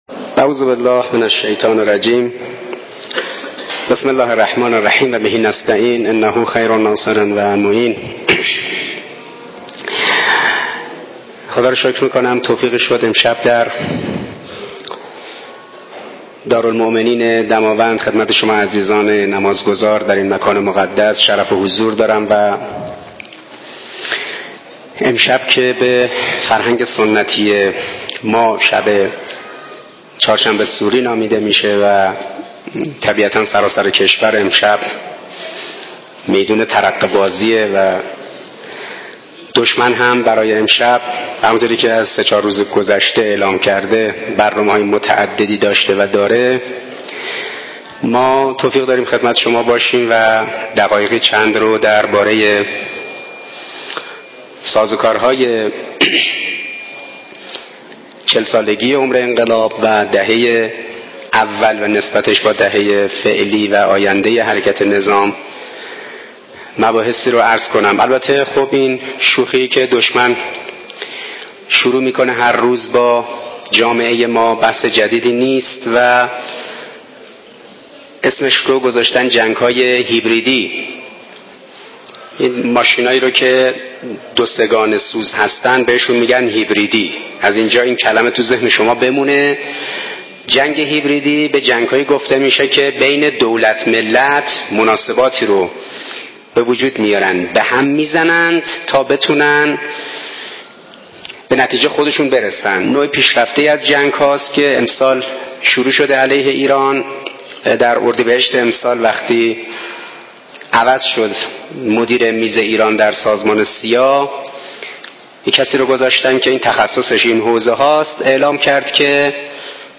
دانلود سخنرانی استاد حسن عباسی با موضوع تاریخ شفاهی دهه ۶۰ انقلاب اسلامی
۲۲ اسفند ۹۶ – شهرستان دماوند، گیلاوند، مسجد الرضا(ع)